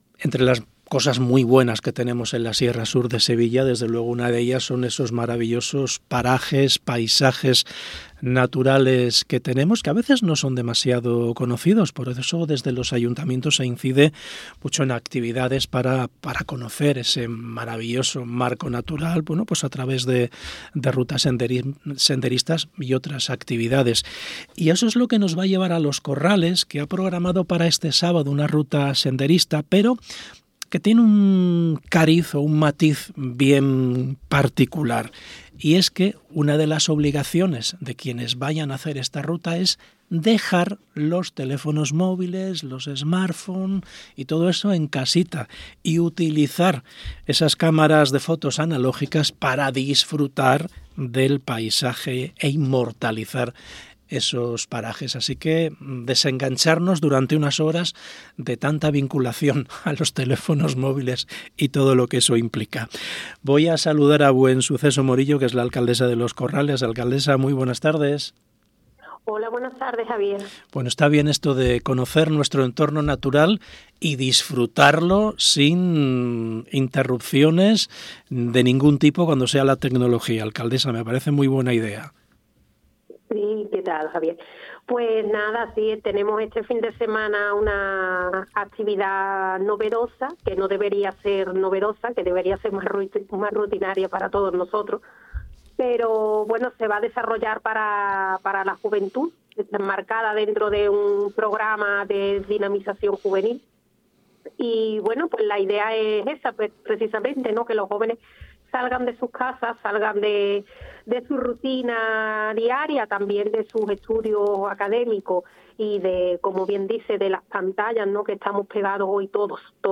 Entrevista Buensuceso Morillo y Cristóbal Zamora
Buensuceso Morillo, alcaldesa de Los Corrales, y Cristóbal Zamora, concejal delegado de Juventud han pasado por el programa Hoy por Hoy SER Andalucía Centro.